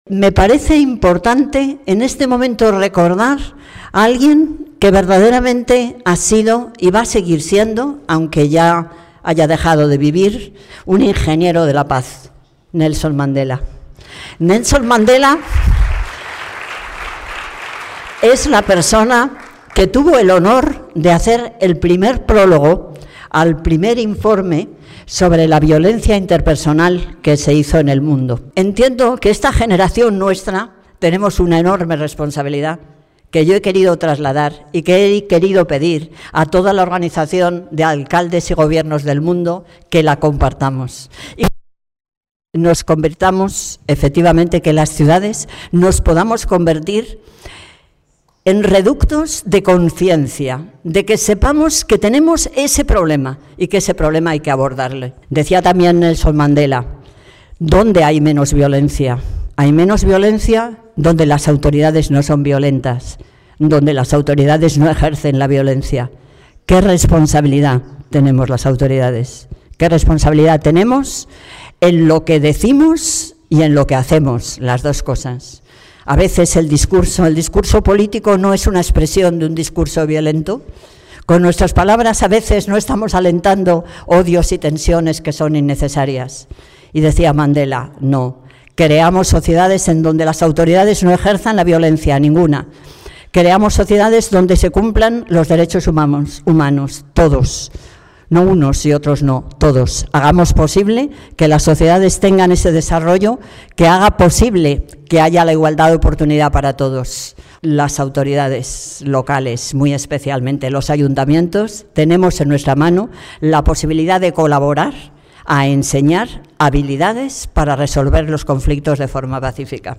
Nueva ventana:Palabras de Manuela Carmena en la Inauguración del Foro